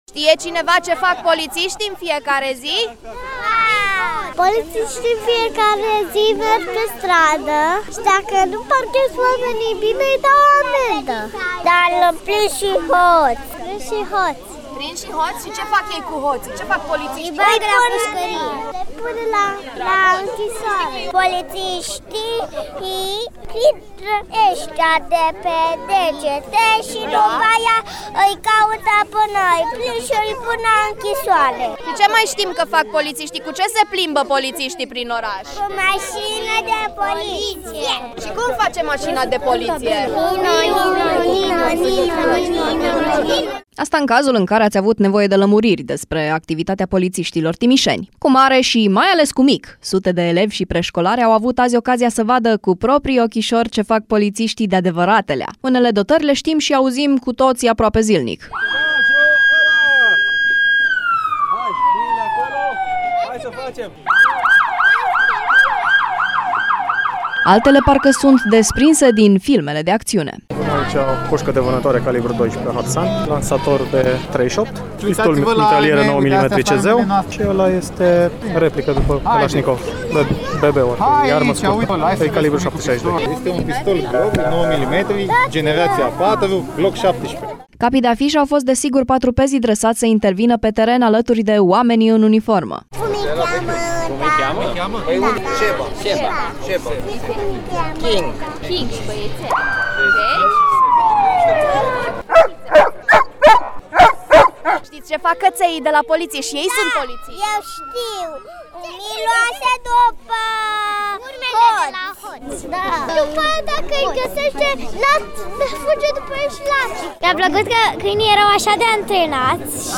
Sute de oameni, majoritatea elevi şi preşcolari, au vizitat sediul Inspectoratului Judeţean de Poliţie Timiş, cu ocazia Zilei porţilor deschise.